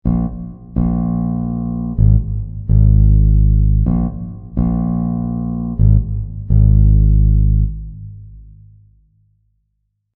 Listen to the syncope in the bass. It sounds like this:
bass-wells.mp3